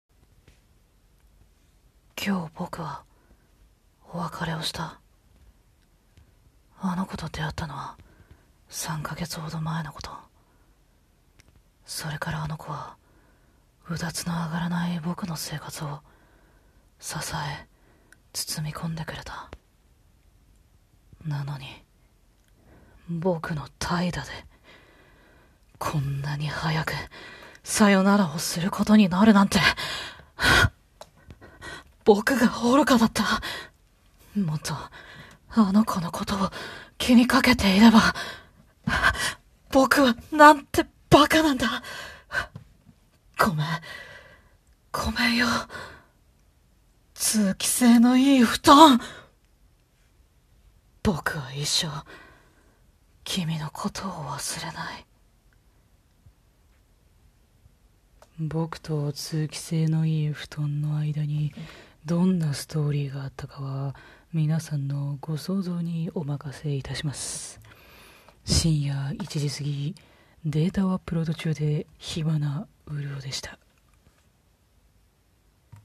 フリートーク #11 声劇風フリートーク